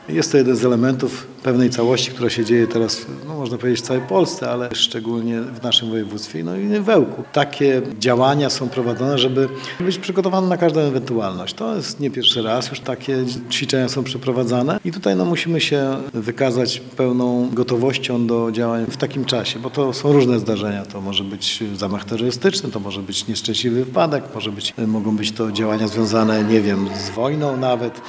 Jak dodaje Marek Chojnowski, starosta powiatu ełckiego, takie ćwiczenia, to część większej całości i są niezbędne, by prawidłowo działać w razie realnych zagrożeń.